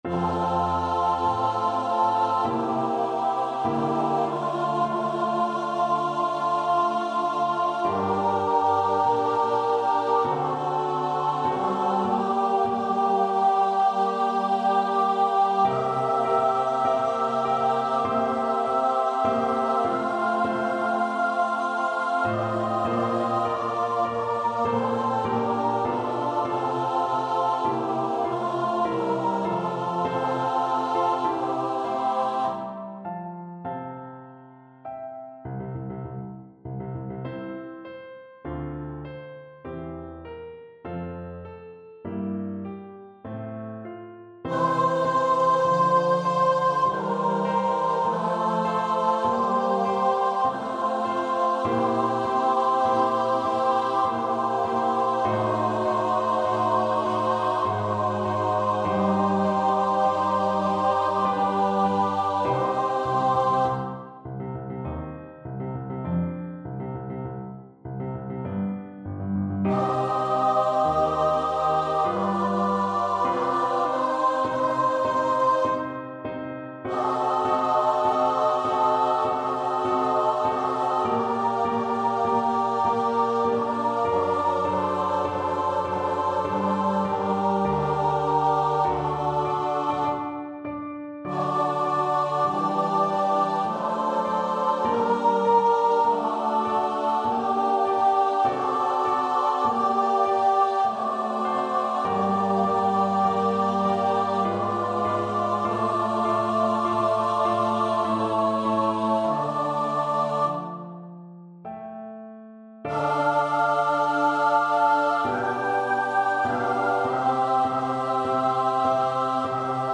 Choir  (View more Intermediate Choir Music)
Classical (View more Classical Choir Music)
haydn_paukenmesse_06_CH.mp3